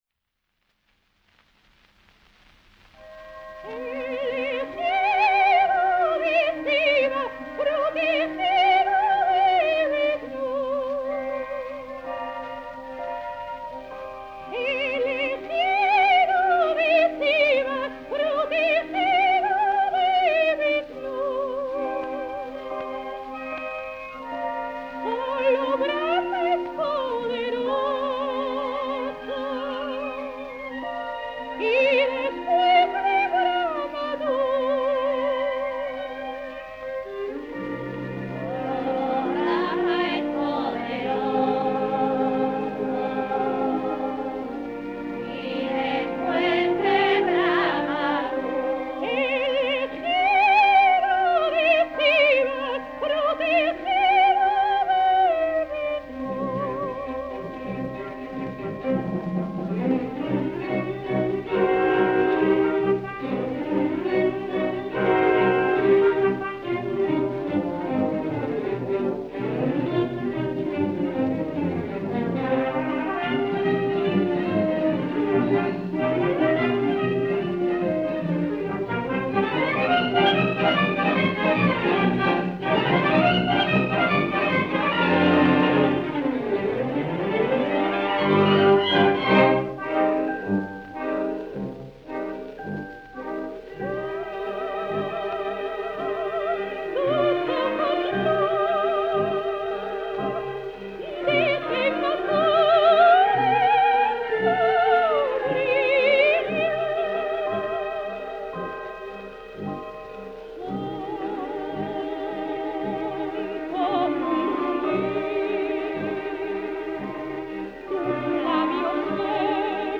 danza
coro, orquesta [78 rpm